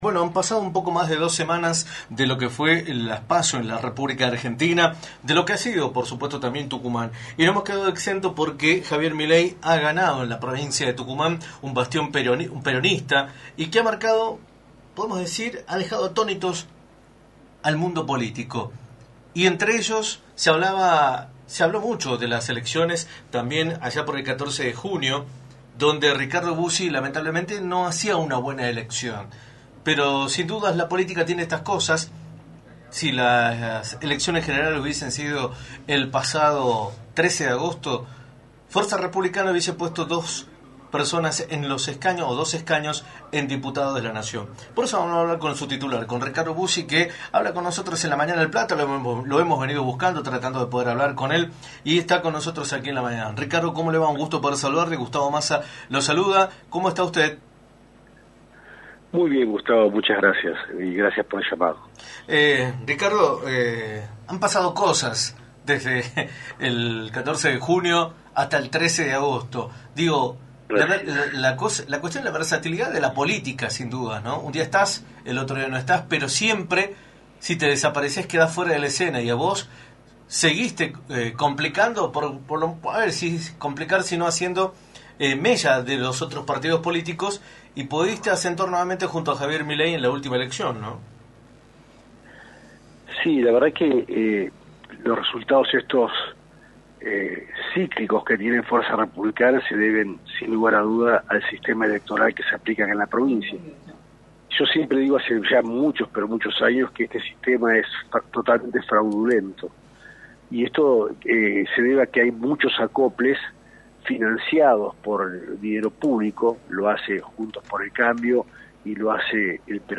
Ricardo Bussi, referente de Fuerza Republicana, Legislador y candidato a Diputado Nacional, analizó en Radio del Plata Tucumán, por la 93.9, los resultados de las elecciones PASO, las cuales tuvieron como candidato más votado a Javier Milei.